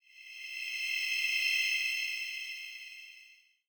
Shimmering-Light-02.mp3